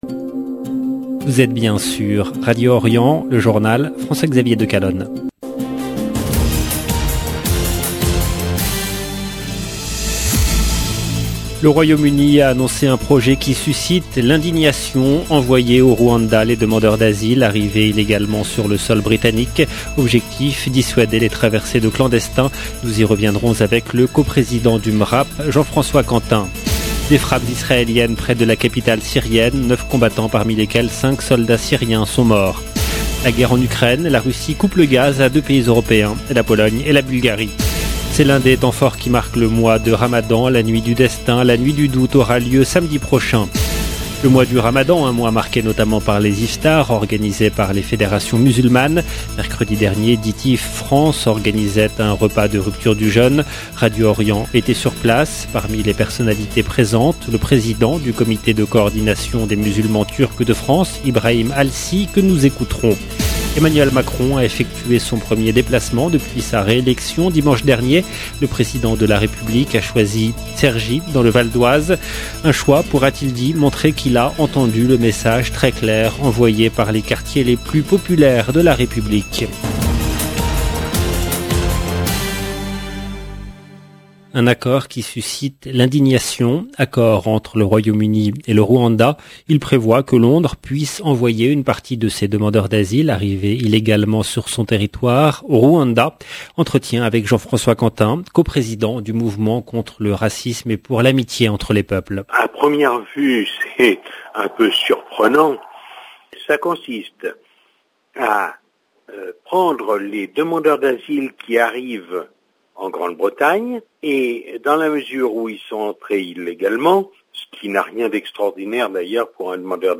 LB JOURNAL EN LANGUE FRANÇAISE
Radio Orient était sur place.